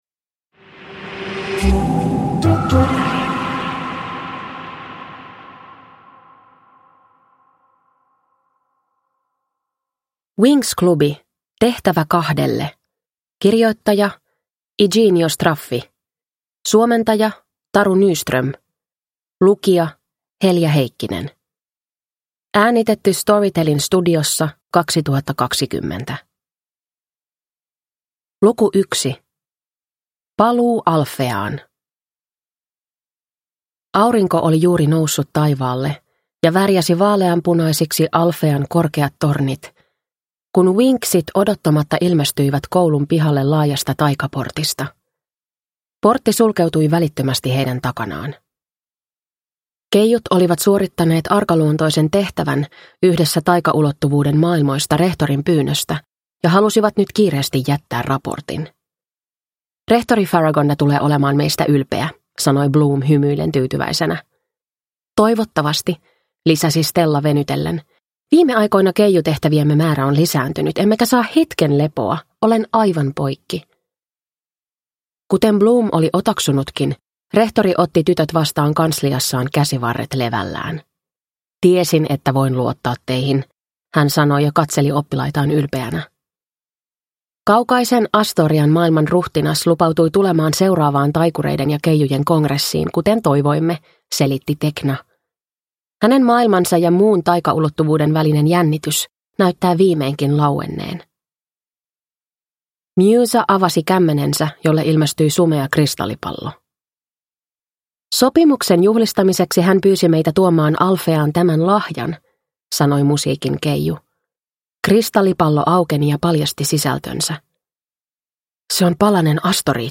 Winx - Tehtävä kahdelle – Ljudbok